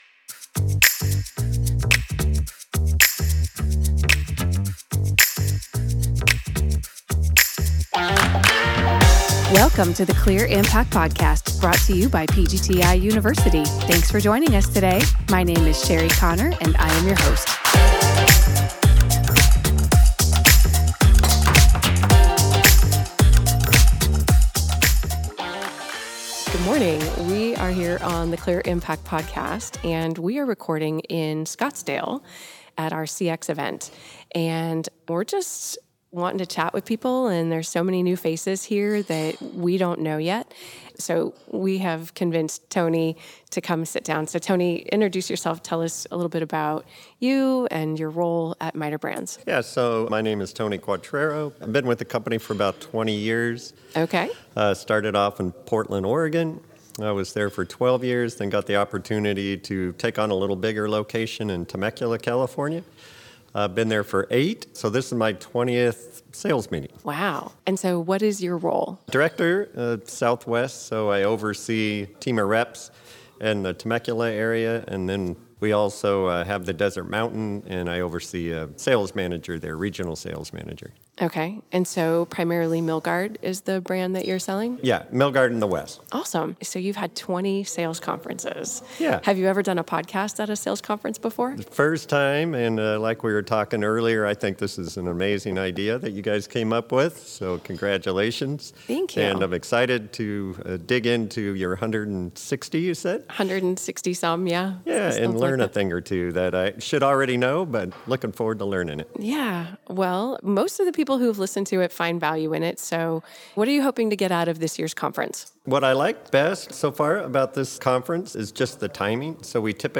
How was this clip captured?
With over 300 people in attendance from across the country, we were able to have several conversations with folks experiencing this inspirational event.